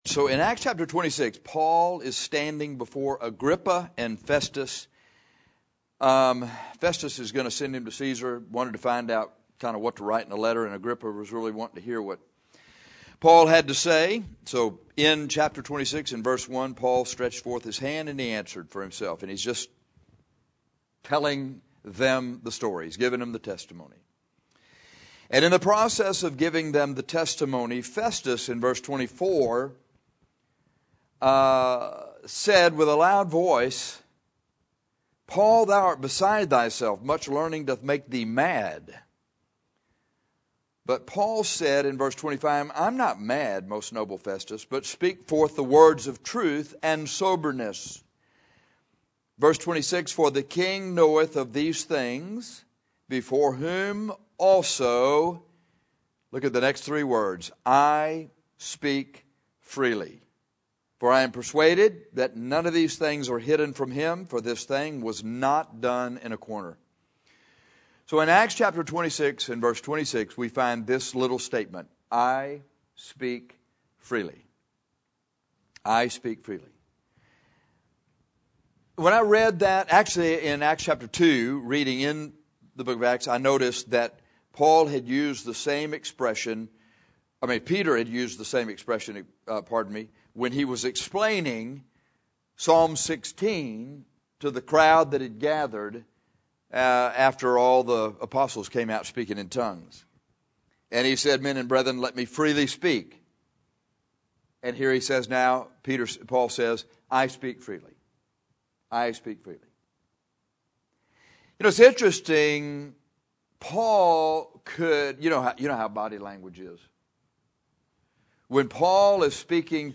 This will be a short sermon with one main point.